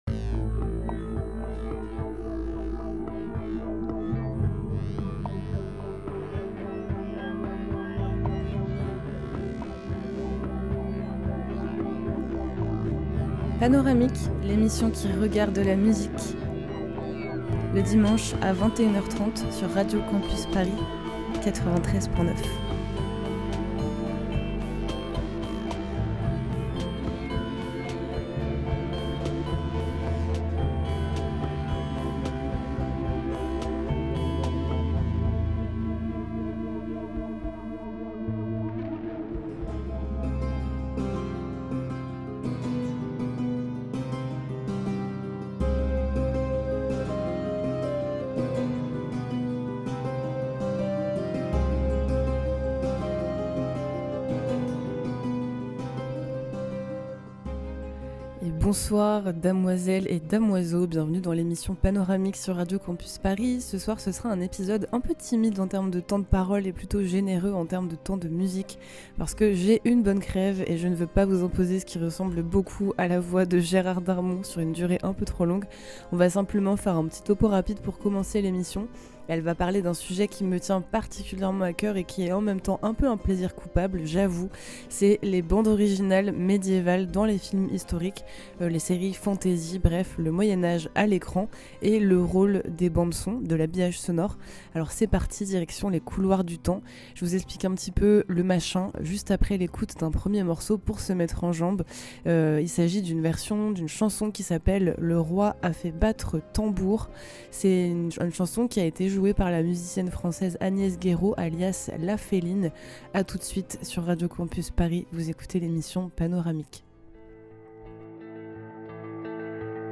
Type Mix